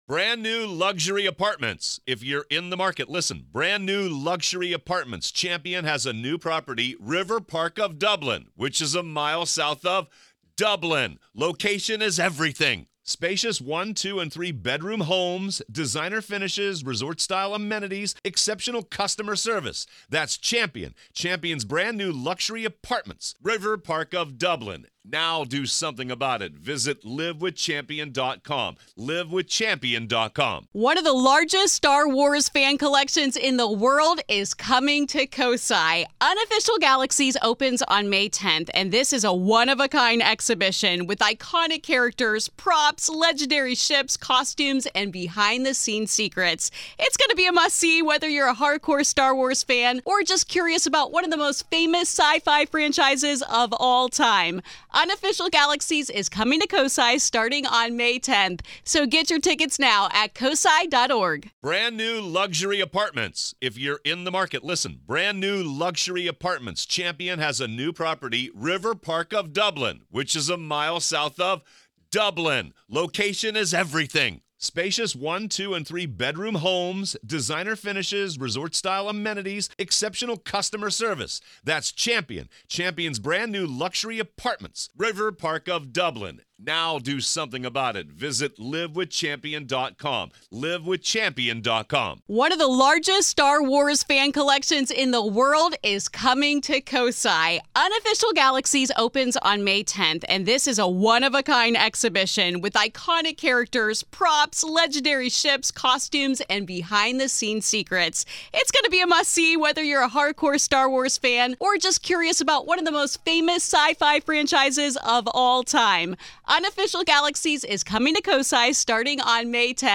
told to us by the man who was once the little boy in this story